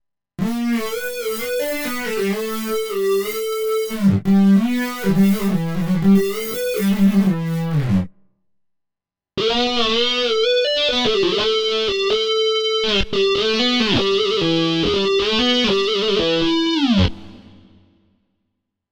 Liquid-lead---normale-e-con-wah.mp3